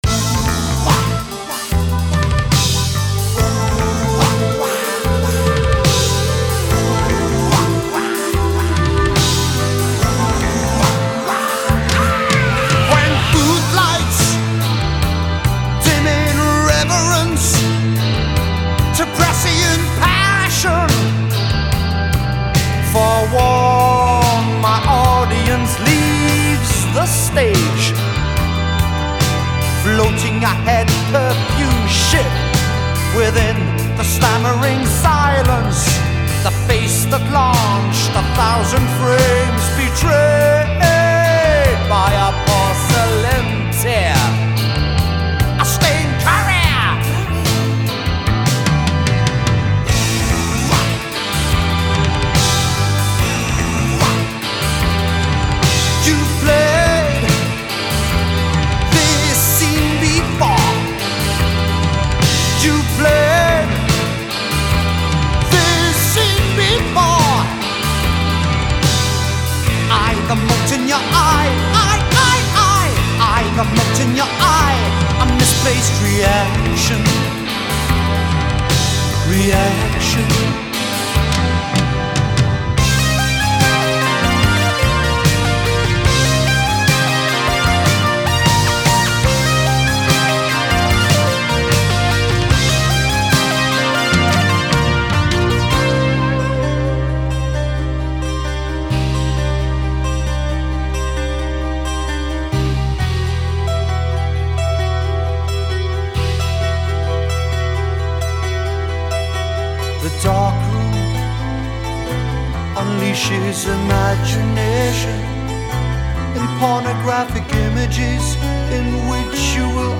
Genre : Progressive Rock